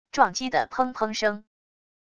撞击的砰砰声wav下载